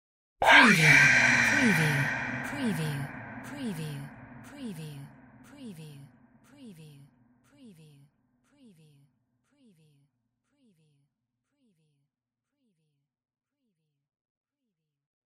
Surreal whisper EYEE 017
SCIFI_WHISPERS_SPOOKY_EYEE_WBSD017
Stereo sound effect - Wav.16 bit/44.1 KHz and Mp3 128 Kbps
previewSCIFI_WHISPERS_SPOOKY_EYEE_WBHD017.mp3